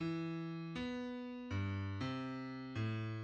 {\clef bass \tempo 4=120 e4. b8 ~ b4 g, c4. a,8 ~ a,8 }\midi{}